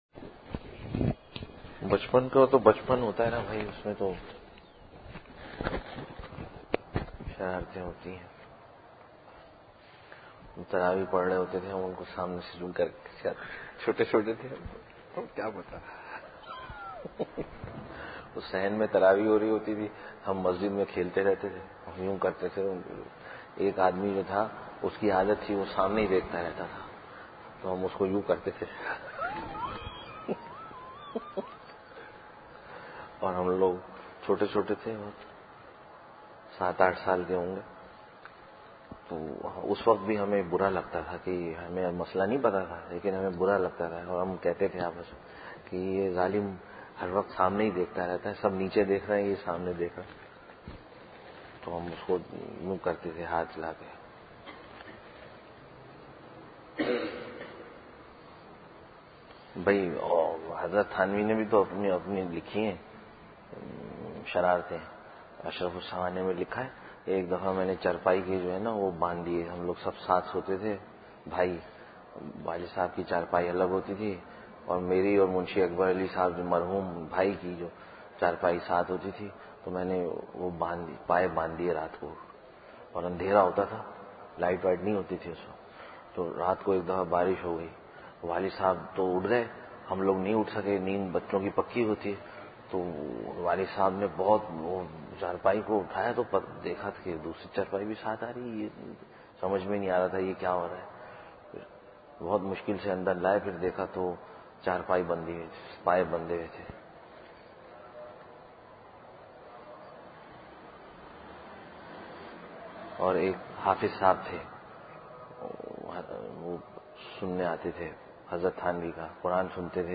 Tarbiyati Lectures - Majliseirshad - Page 11